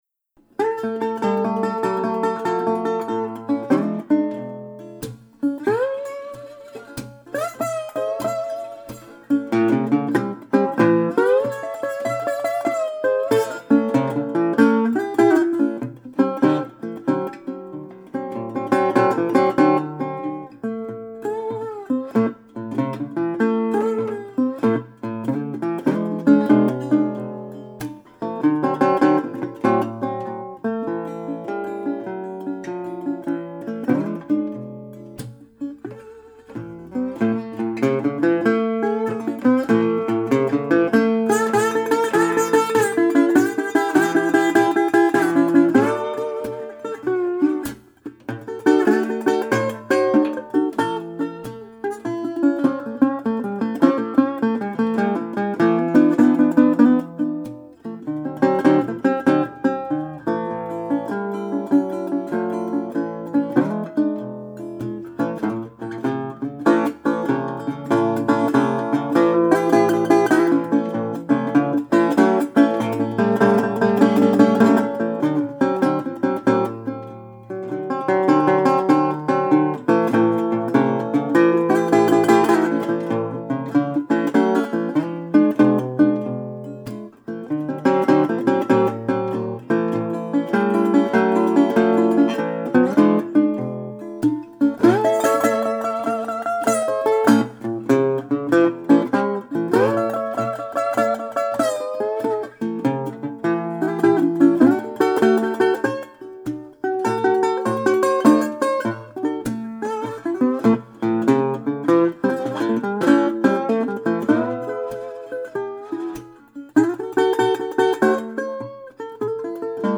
Slide Delta Blues with the "Half-Open A Slide" Effect
Unreleased, recorded in 1994 on my home 4-track machine.
solo slide resonator guitar